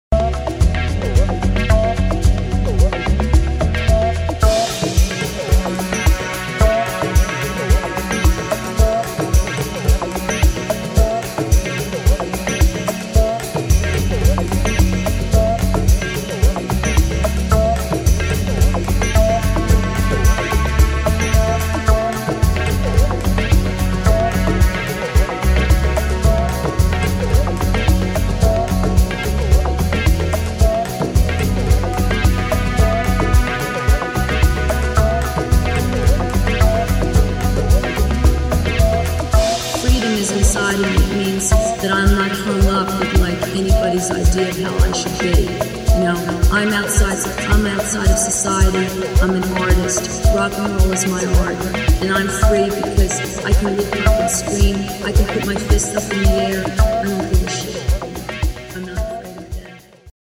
[ BALEARIC / DISCO / DOWNTEMPO ]
UKバレアリック・デュオ